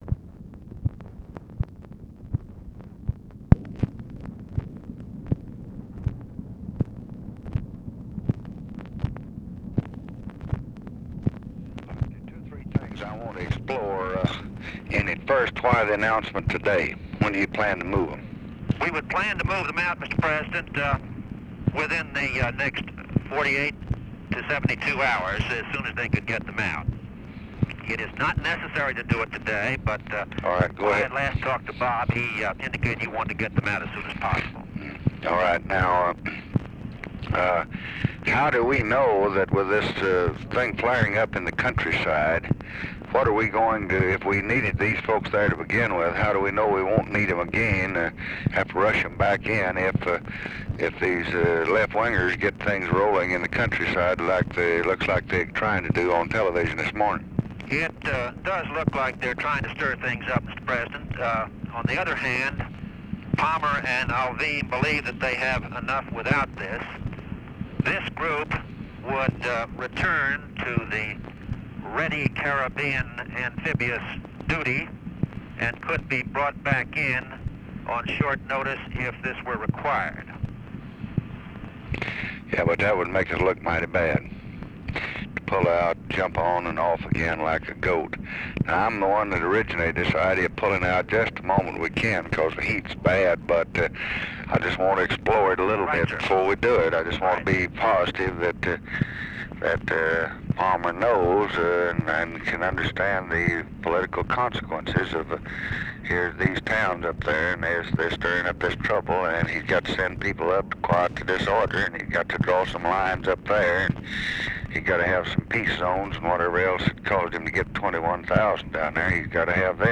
Conversation with CYRUS VANCE, May 31, 1965
Secret White House Tapes